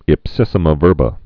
(ĭp-sĭsə-mə vûrbə)